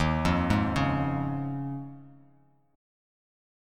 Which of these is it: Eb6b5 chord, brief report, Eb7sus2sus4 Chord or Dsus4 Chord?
Eb7sus2sus4 Chord